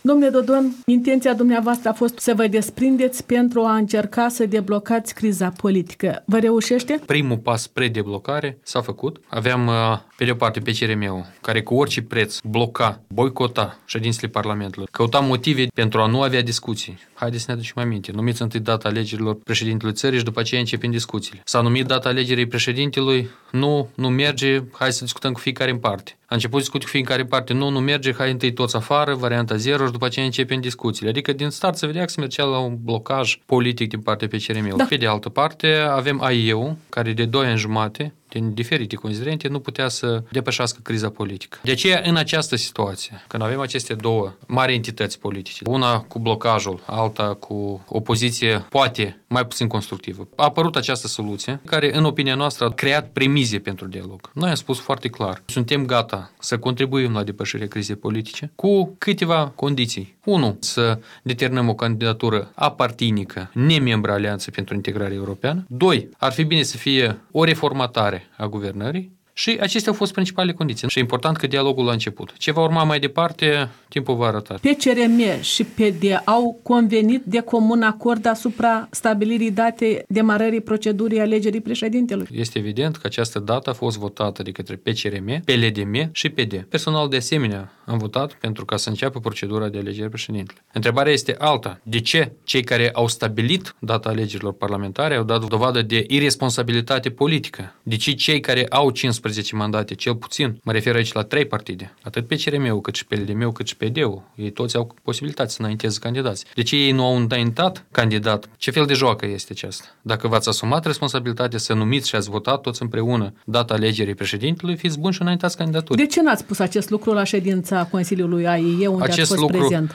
Igor Dodon răspunde întrebărilor